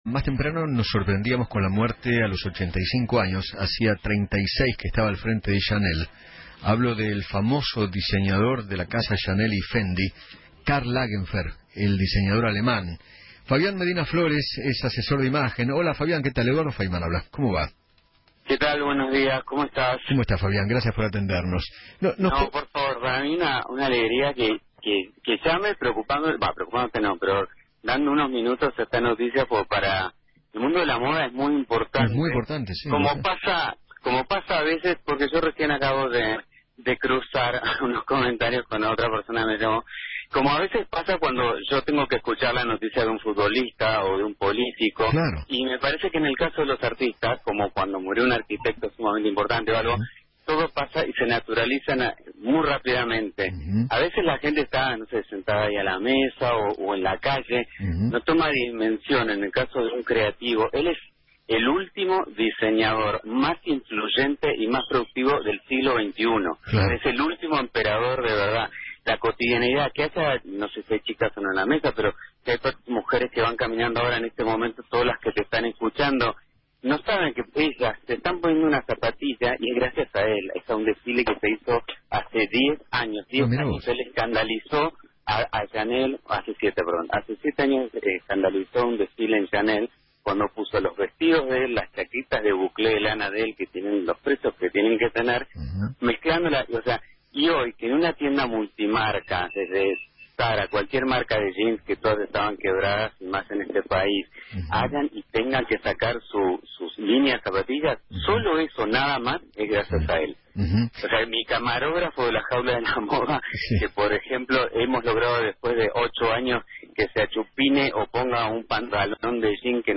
asesor de imagen